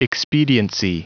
Prononciation du mot expediency en anglais (fichier audio)
Prononciation du mot : expediency